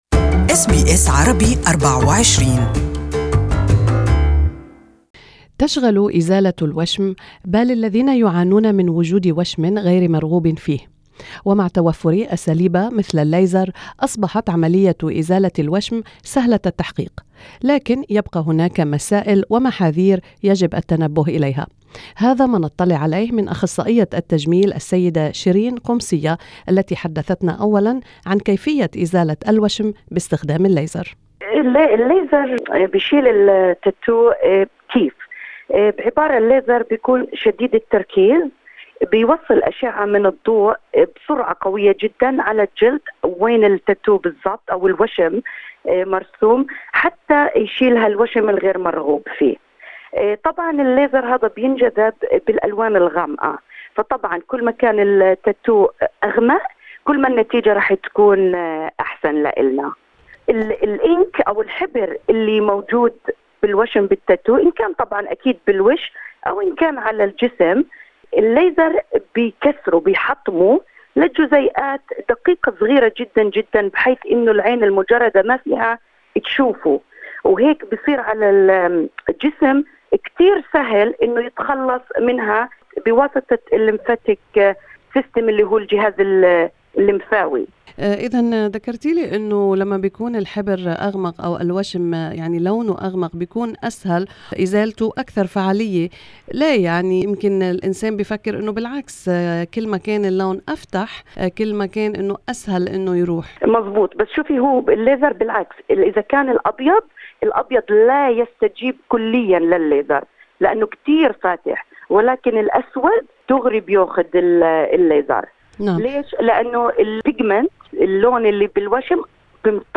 Beauty specialist